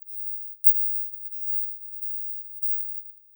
I produced a 5000Hz (AM Voice) track and smashed it onto a 20,000 constant tone carrier.
If you do a spectrum inspection, you find that everything is clustered around 20,000Hz and even though it’s perfectly loud, its completely silent.